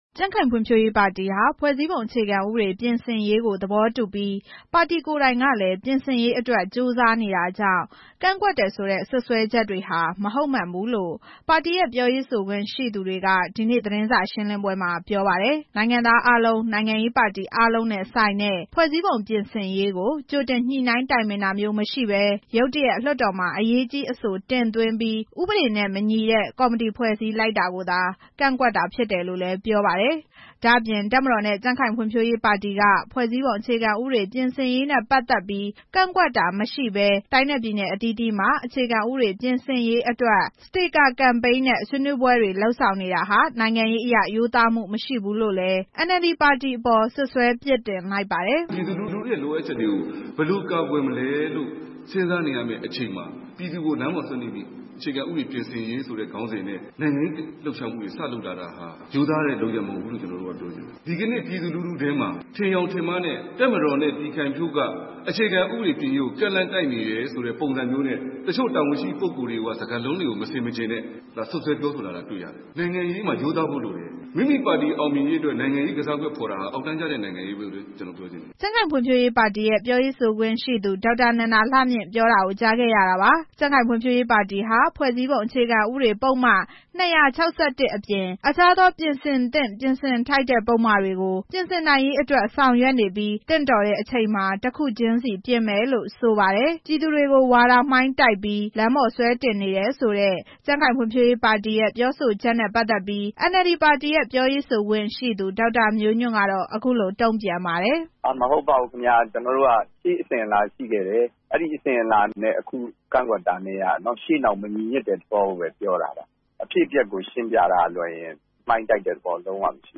ဒီနေ့ နေပြည်တော်မှာ ပြုလုပ်တဲ့ ကြံ့ခိုင်ဖံ့ွဖြိုးရေးပါတီ သတင်းစာရှင်းလင်းပွဲမှာ